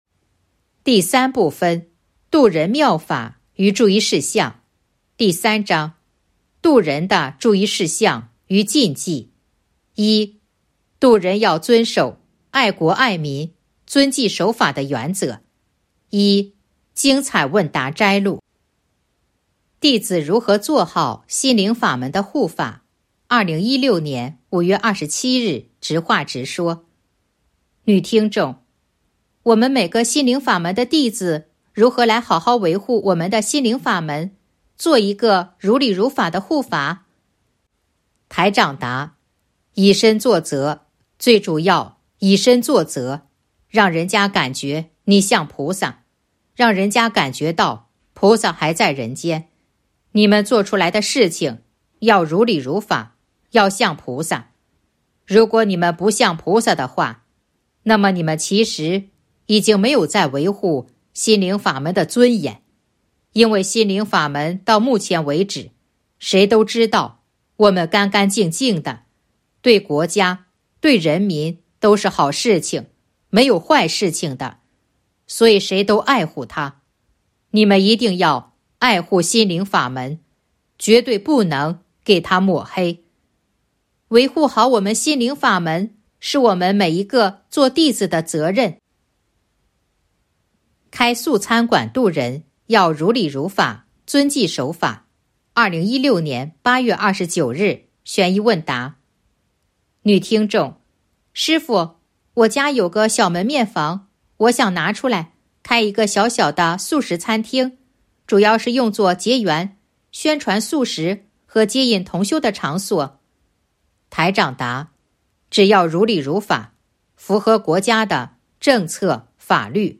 051.1. 精彩问答摘录《弘法度人手册》【有声书】